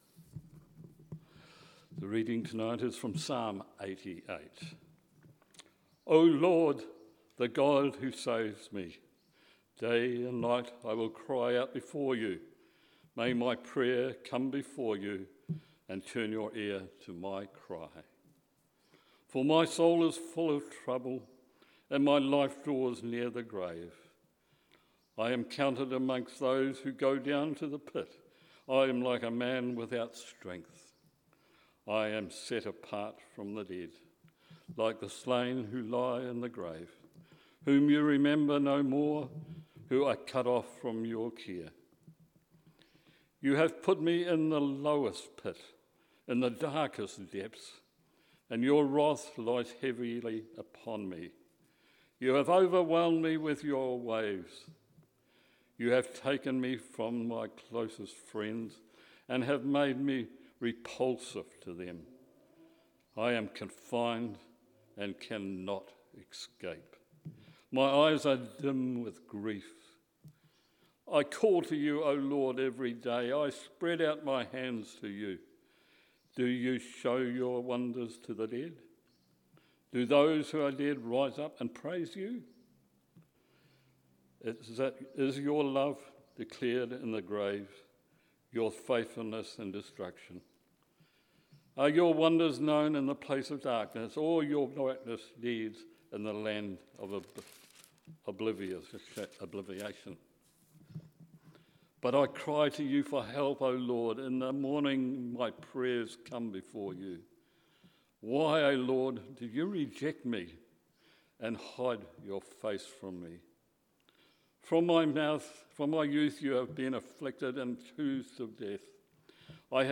A homily for the Blue Advent Evensong